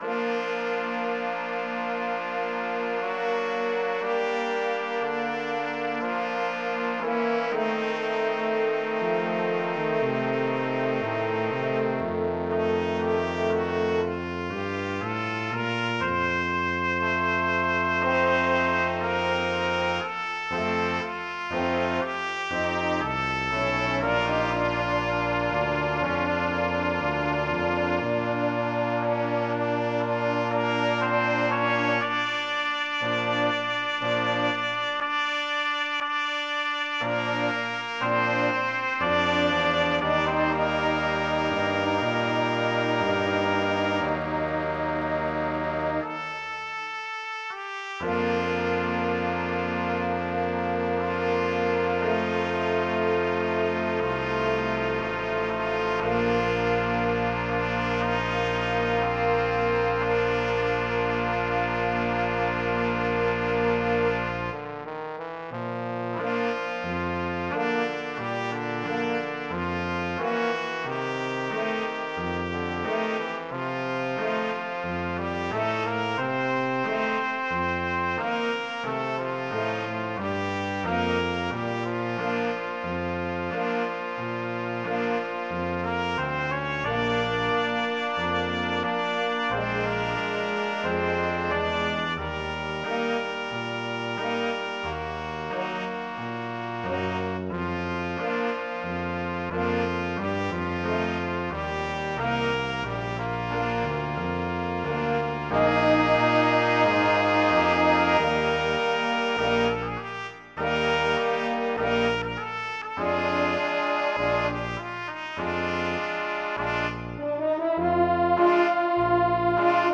Gattung: für Blechbläserquintett
Ensemblemusik für Blechbläser-Quintett PDF